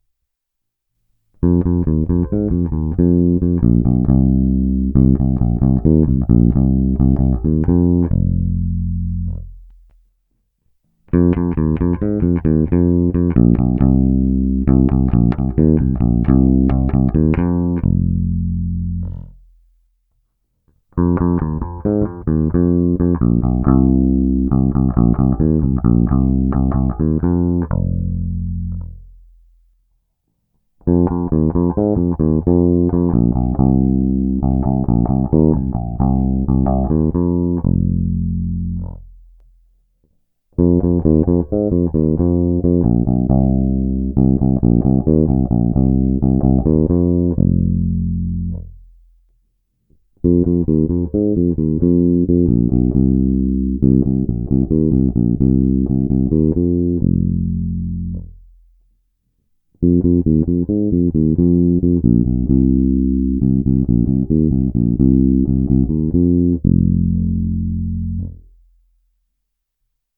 Ukázky s jiným motivem ve stejném pořadí jako výše:
Určitě jste si všimli, jak se charakter s rostoucím číslem filtru víc a víc zastírá, ale zároveň se zdůrazňují jiné středové frekvence a to od vyšších středů po ty nižší. U pozice 1 slyším i pokles basů oproti pasívnímu režimu a zároveň nárůst výšek, zvuk je opravdu hodně jasný.